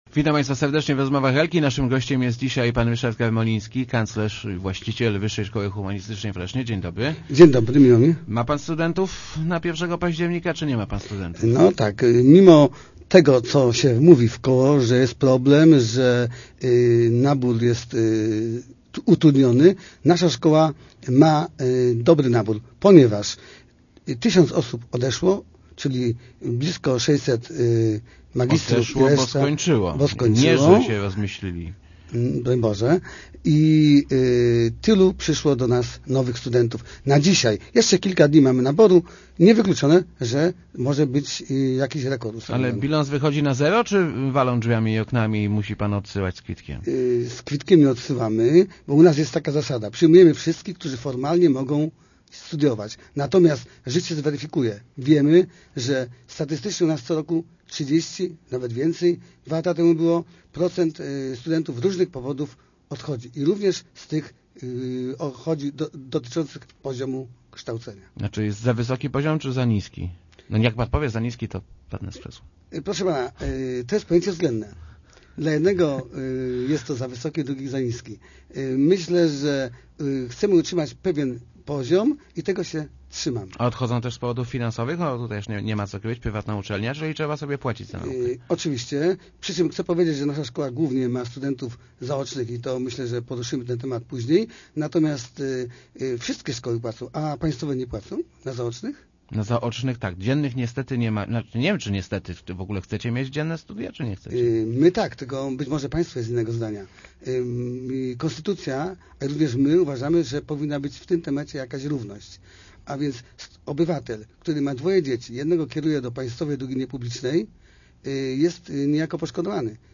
23.09.2009. Radio Elka